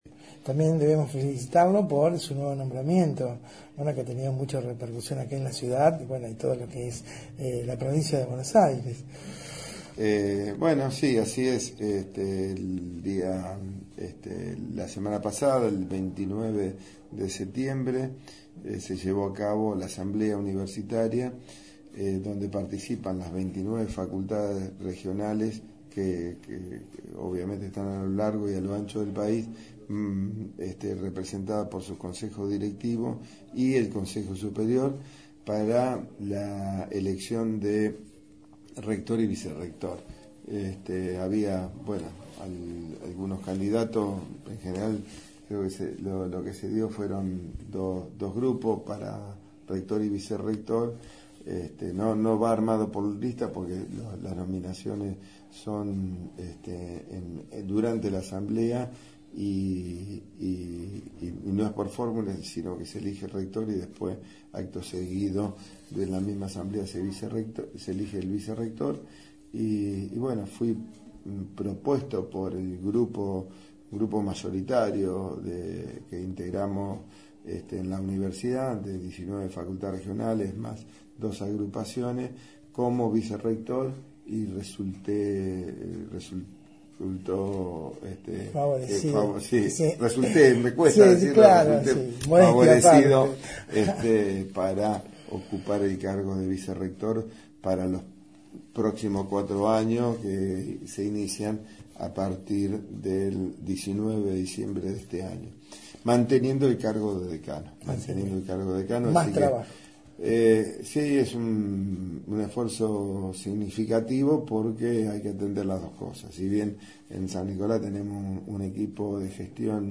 Audio: Charla con el Ing.